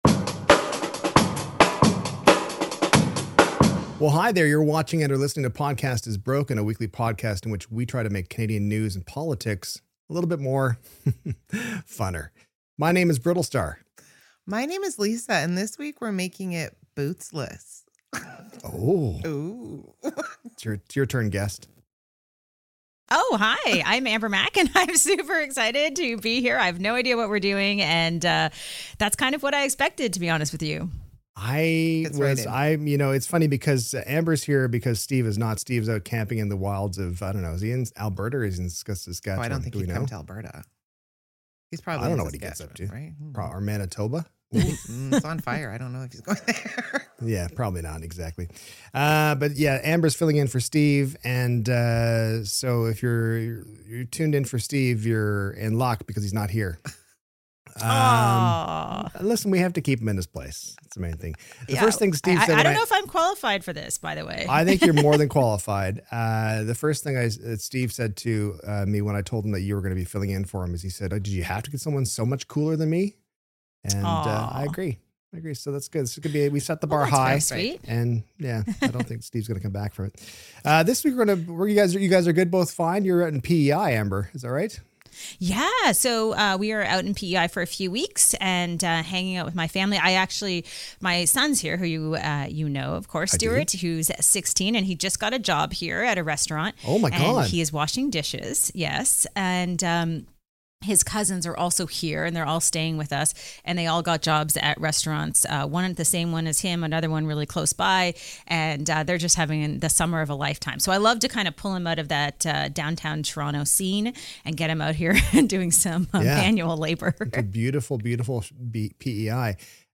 Bonus: The episode ends with possibly the finest improvised theme song ever recorded using a piece of paper, pens, and a phone dial pad.